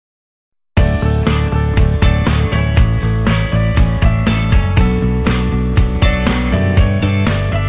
call_ringtone2.wav